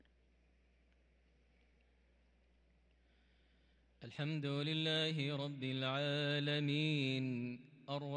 صلاة العشاء للقارئ ماهر المعيقلي 30 جمادي الأول 1444 هـ
تِلَاوَات الْحَرَمَيْن .